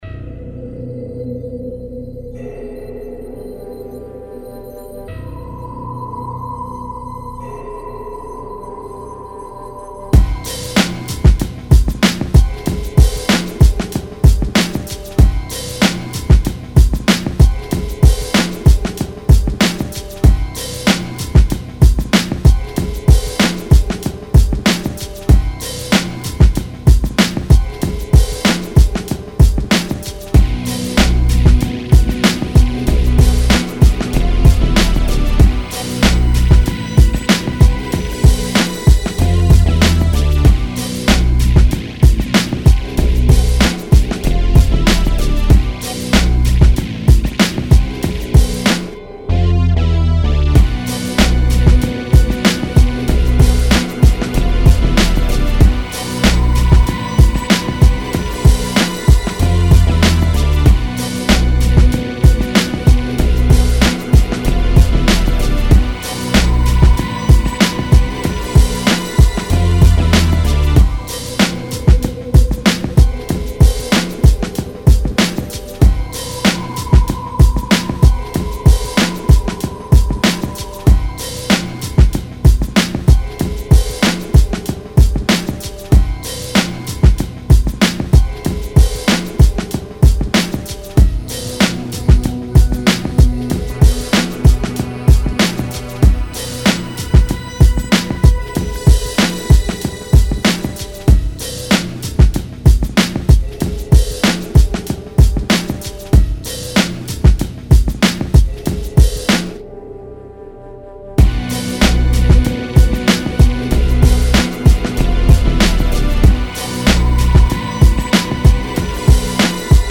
95 BPM.